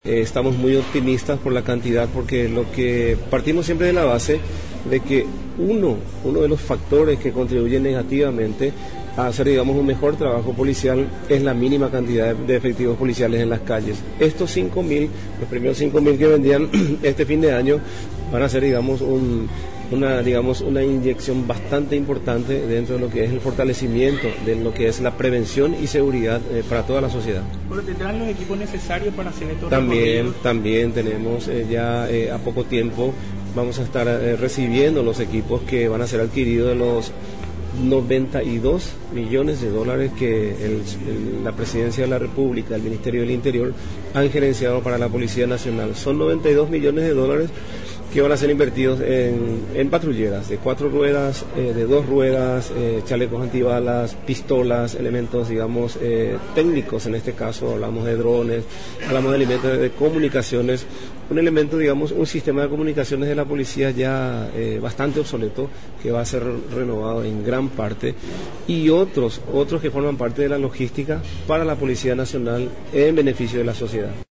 Con la finalidad de fortalecer el combate contra la inseguridad, la Policía Nacional, se encuentra en proceso de adquirir nuevos equipos informáticos, patrulleras, motocicletas y armas de fuego, destacó este lunes el comandante de la institución, el comisario, Carlos Benítez.